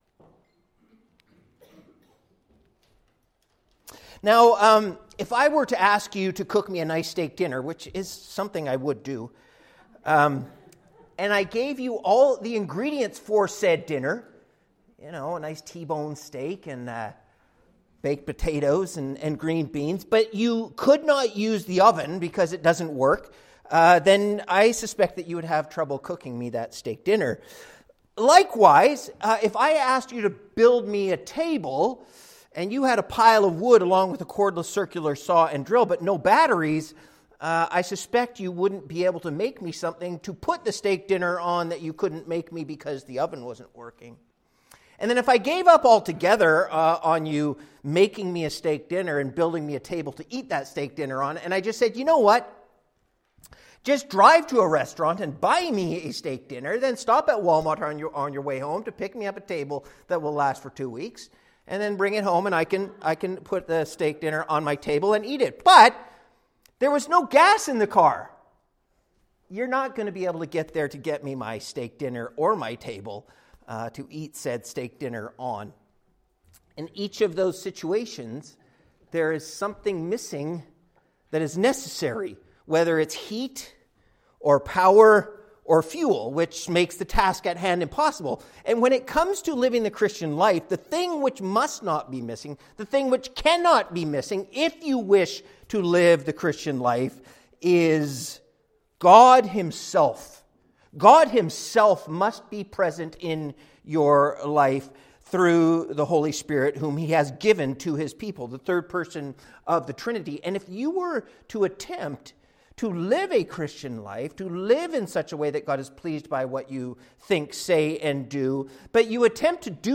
Halifax Reformed Baptist Sermons